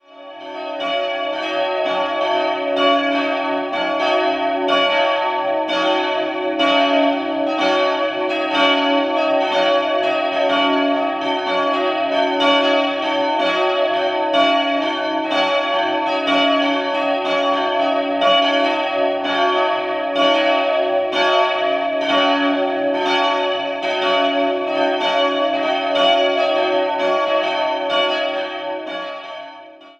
3-stimmiges Gloria-Geläut: cis''-dis''-fis'' Die beiden größeren Glocken wurden im Jahr 1983 von der Karlsruher Glocken- und Kunstgießerei gegossen. Die kleine, unbezeichnete Glocke ist historisch.